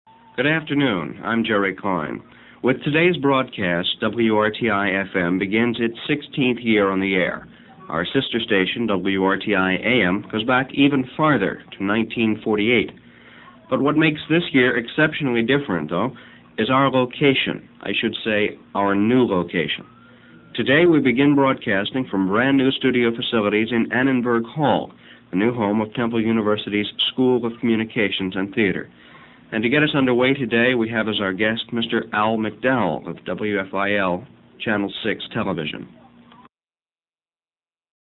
The First Broadcast from Annenberg Hall
Introduction to the ceremonies